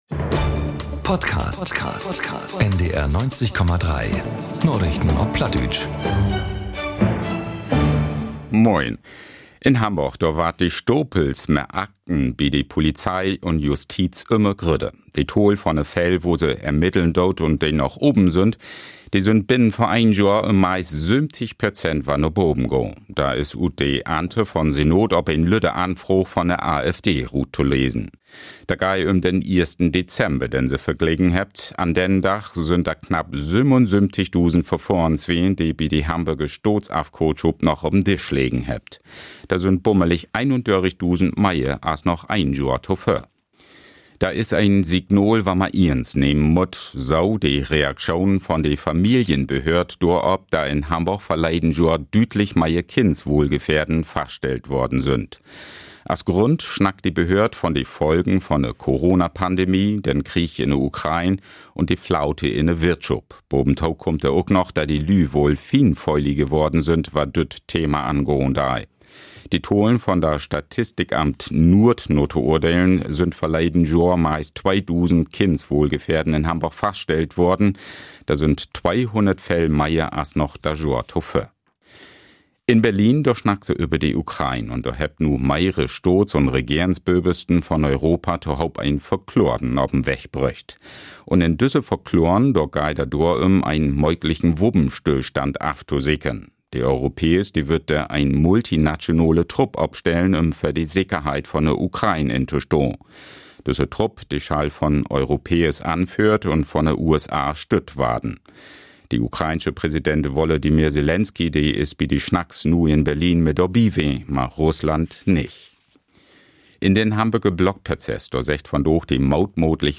Narichten op Platt 16.12.2025 ~ Narichten op Platt - Plattdeutsche Nachrichten Podcast